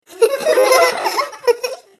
数人の子供たちが楽しそうに笑っている声。少し怖い感じもする音声です。